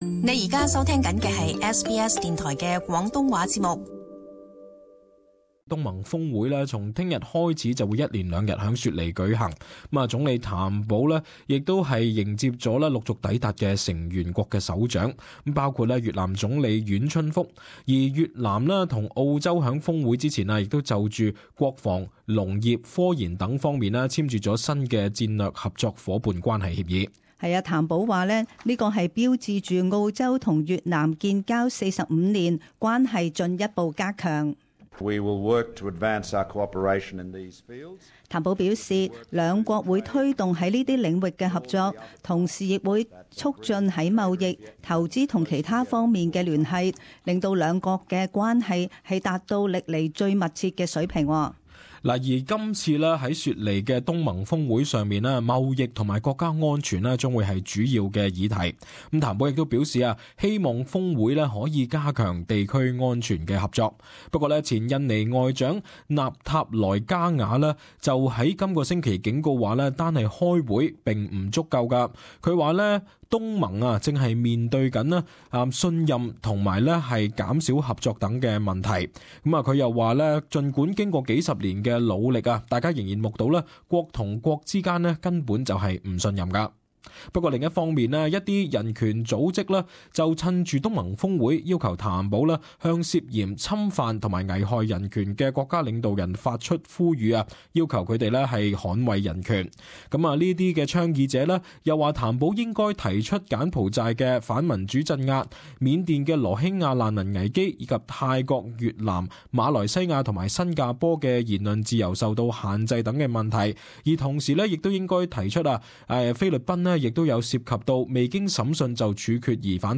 【时事报导】东盟峰会